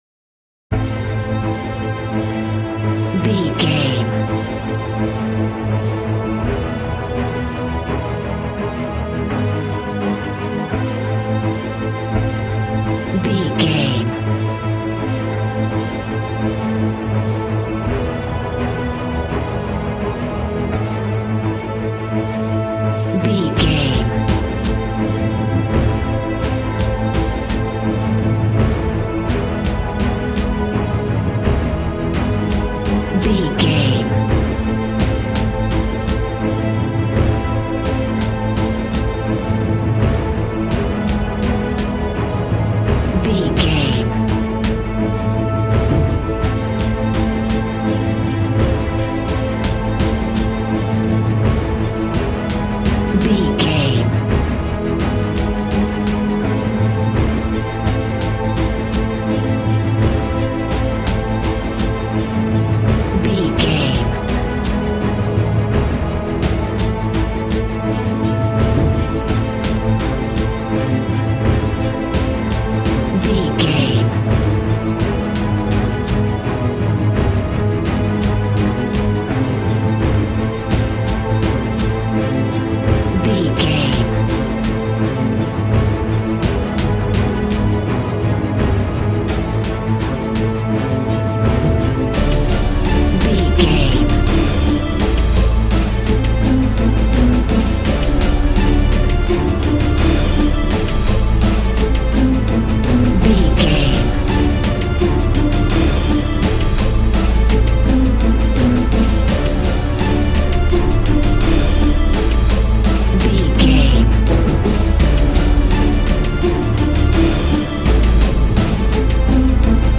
Ionian/Major
strings
percussion
synthesiser
brass
violin
cello
double bass